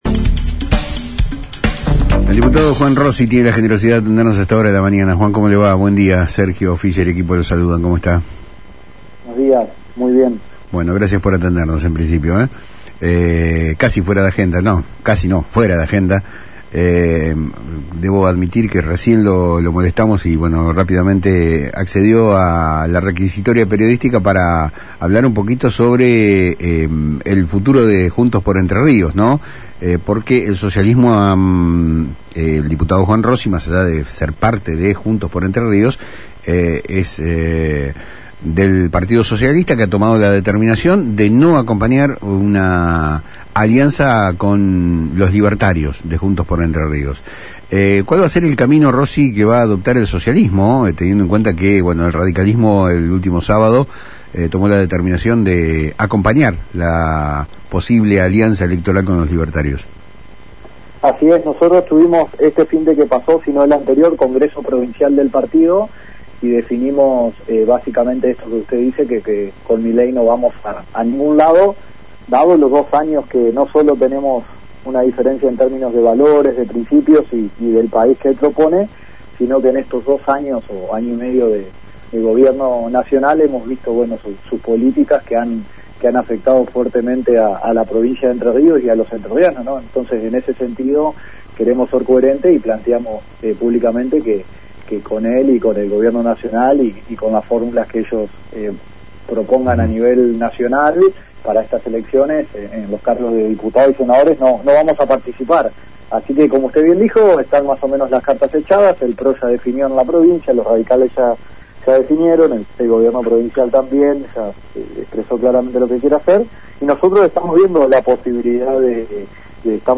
El diputado Juan Rossi, presidente del Partido Socialista en Entre Ríos, confirmó, ante los micrófonos de Palabras Cruzadas por FM Litoral de Paraná, que su partido no formará parte de una alianza electoral con los libertarios de La Libertad Avanza (LLA).